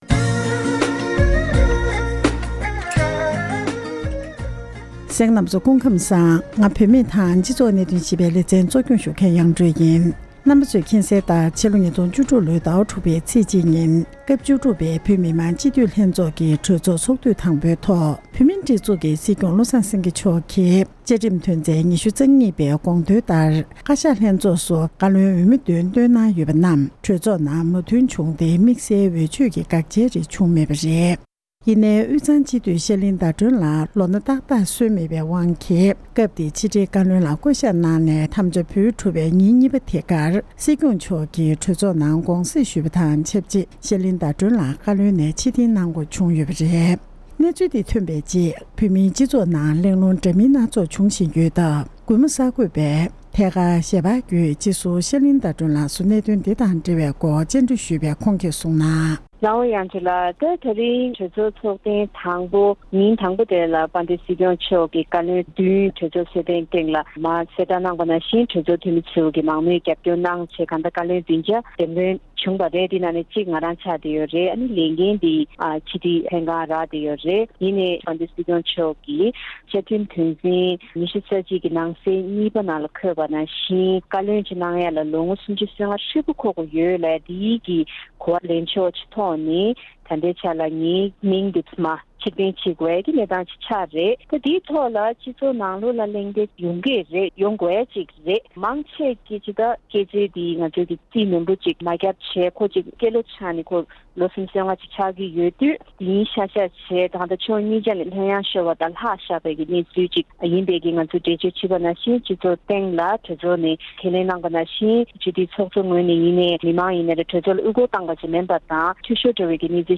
སྒྲ་ལྡན་གསར་འགྱུར།
དོ་བདག་དང་ཐད་ཀར་གླེང་མོལ་ཞུས་པ་ཞིག